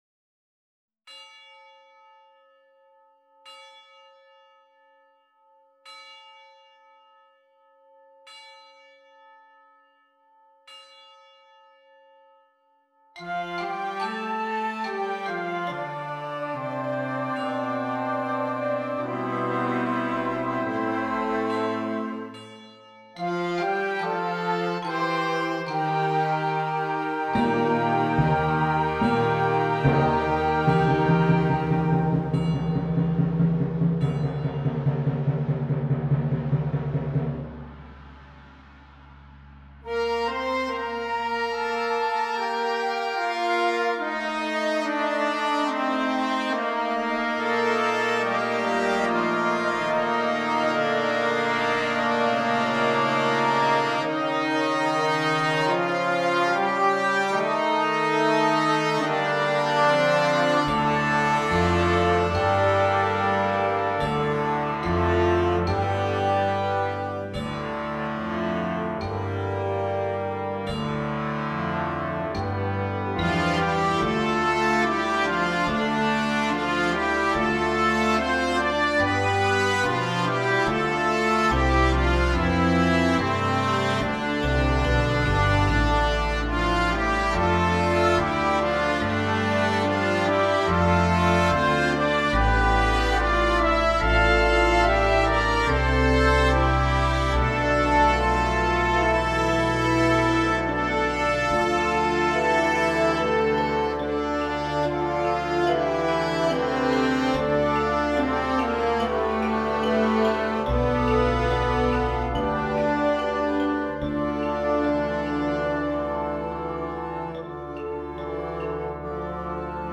It is a grade 2.5 Concert / Flex band piece.